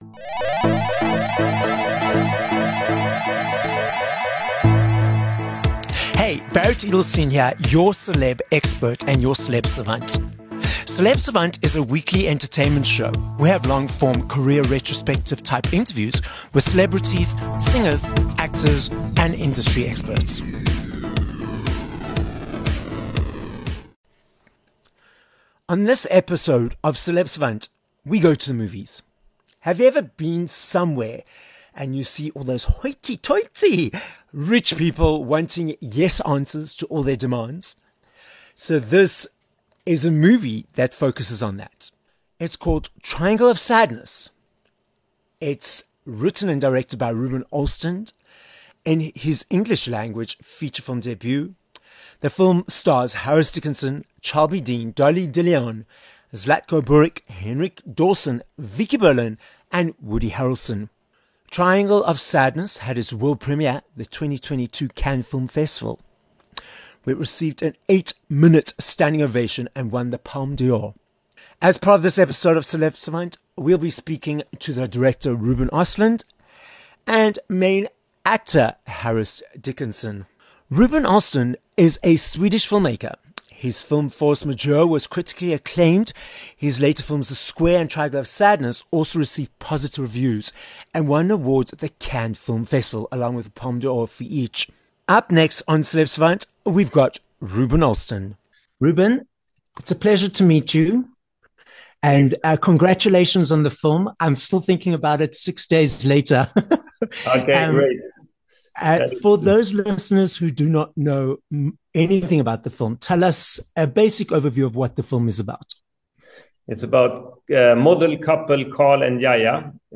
3 Nov Interview with Ruben Ostlund and Harris Dickinson from Triangle of Sadness